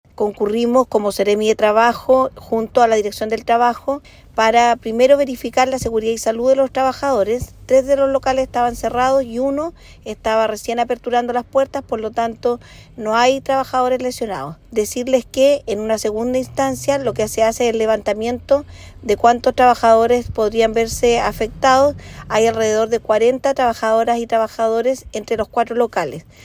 El Gobierno hizo un catastro y la seremi del Trabajo y Previsión Social, Claudia Tapia, indicó que 40 trabajadores podrían perder sus fuentes de empleo.